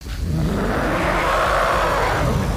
KaijuNo.8roar.mp3